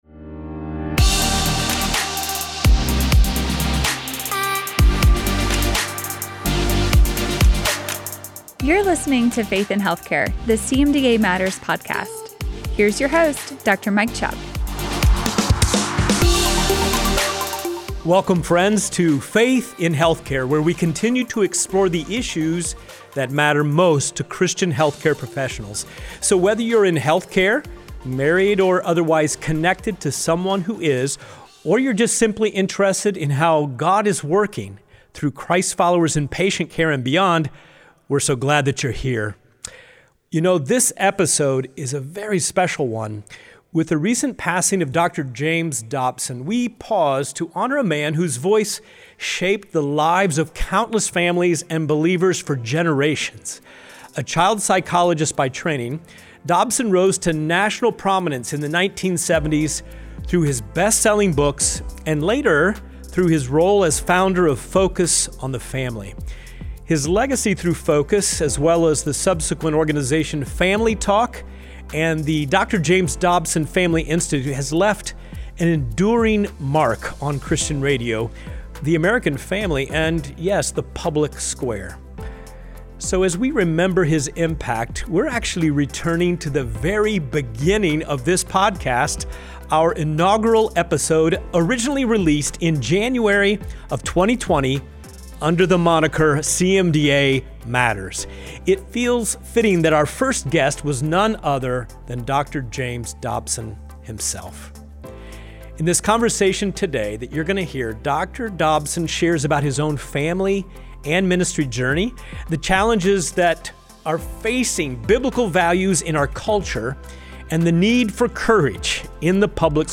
In honor of his impact, we’re returning to our very first podcast episode from January 2020 under the name CMDA Matters, where Dr. Dobson joined us as the inaugural guest. In this conversation, he speaks with honesty and conviction about family, faith, cultural pressures, and the courage needed to uphold biblical values in the public square.